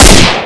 assets/pc/nzp/sounds/weapons/kar/shoot.wav at af6a1cec16f054ad217f880900abdacf93c7e011
shoot.wav